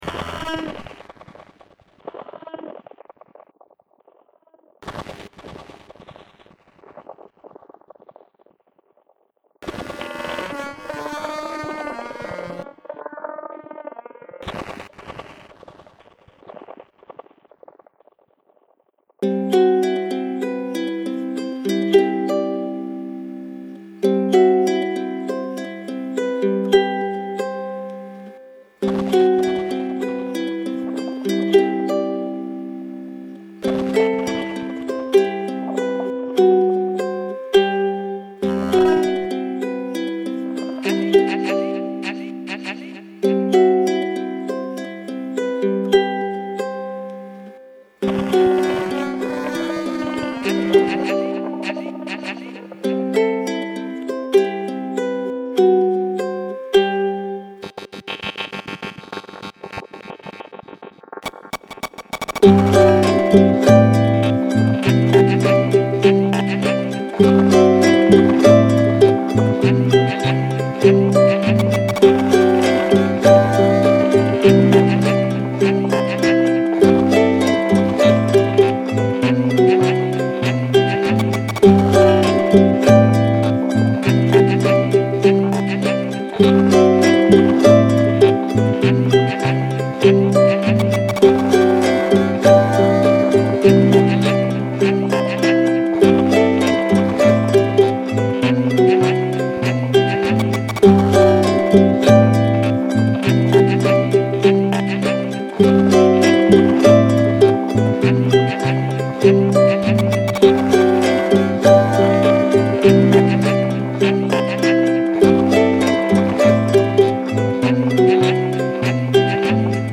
an ukulele, a radio and percussions.